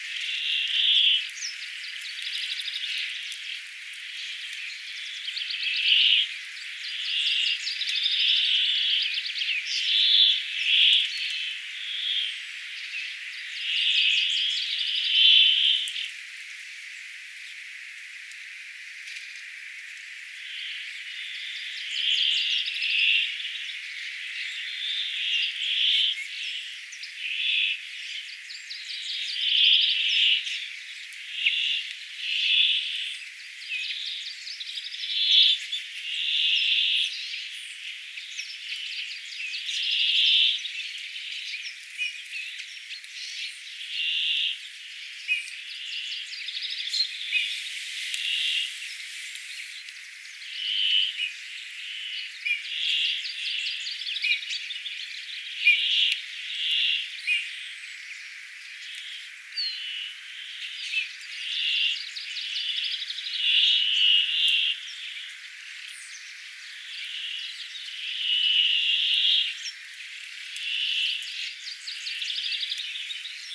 järripeipon "laulua"
Voiko järripeipon ääntelyä sanoa lauluksi?
jarripeippojen_laulua_hieman_kylla_karkeeta_on_ei_oikein_lauluksi_voi_kutsuu.mp3